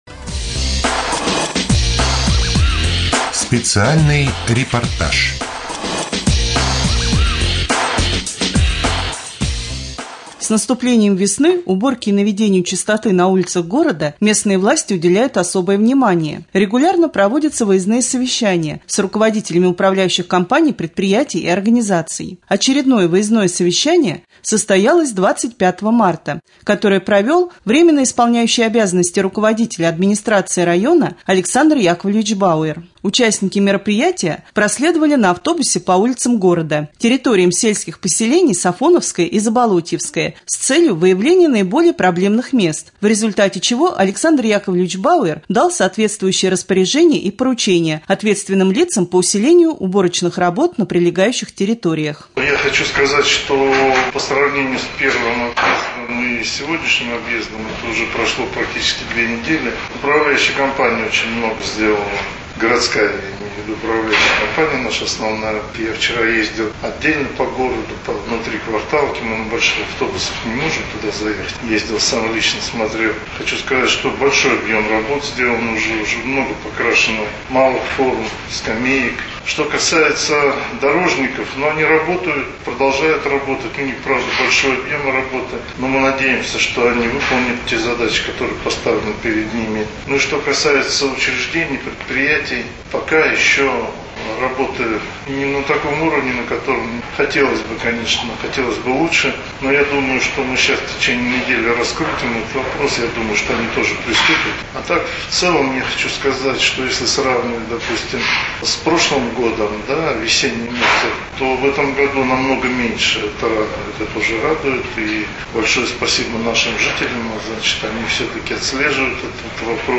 01.04.2014г. в эфире раменского радио - РамМедиа - Раменский муниципальный округ - Раменское
4.Рубрика «Специальный репортаж». 25 марта прошло выездное совещание с целью проверки уборочных работ.